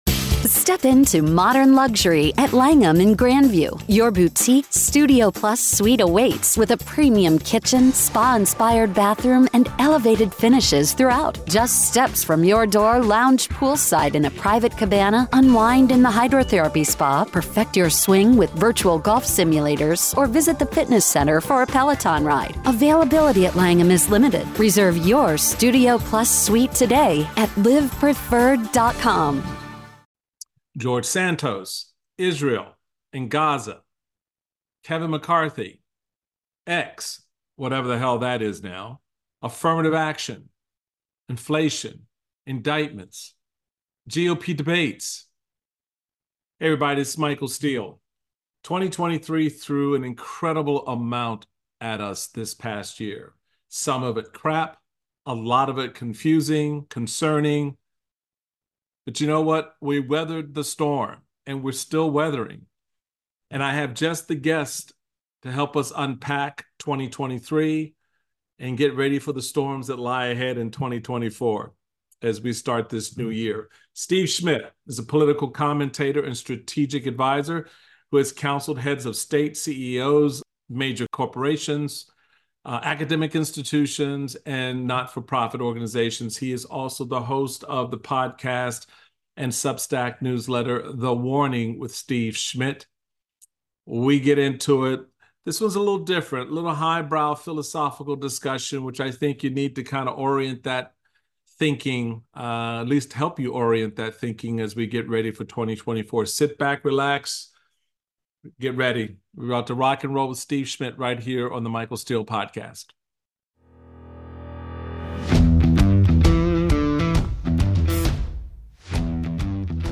Michael Steele speaks with Steve Schmidt about how the disruptions that occurred in 2023 will shape 2024, the messaging of the Biden Administration and Democratic challengers such as Dean Philips.